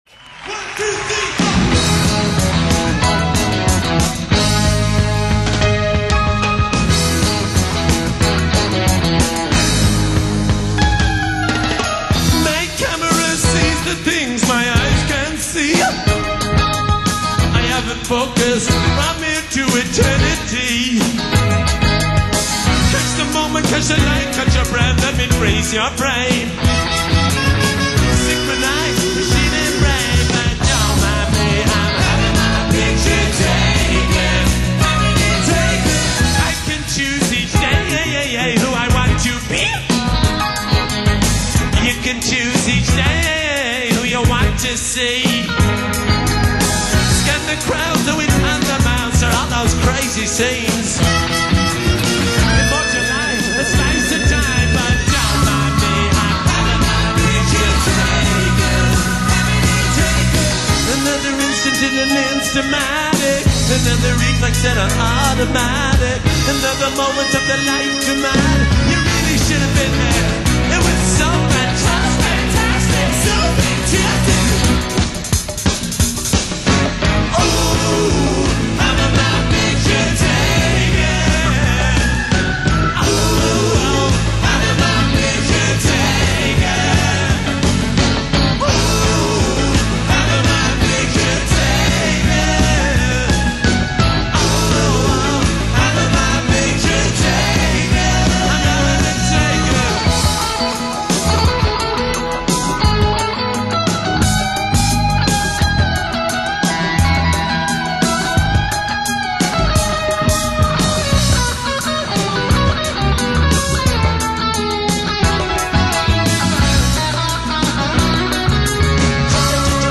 Live At Hammersmith Odeon